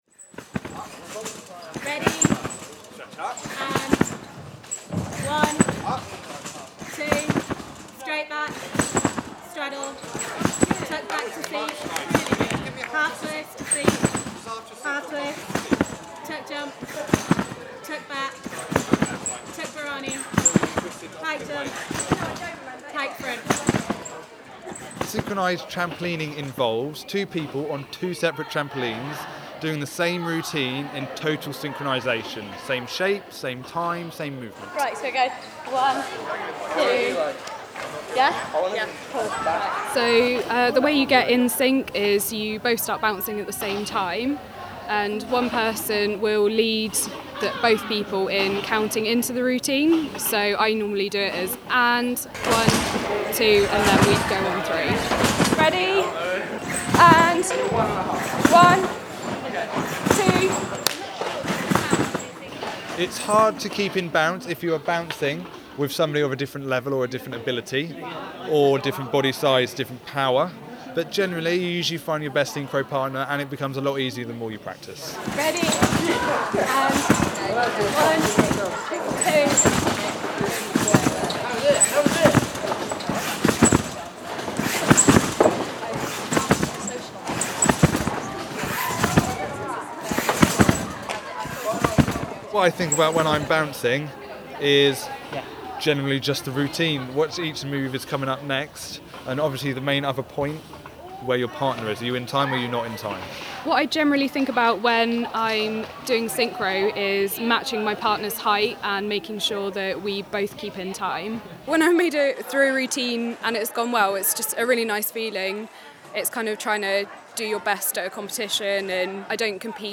Thanks to Rossmore Leisure Centre and Bournemouth University for recording space.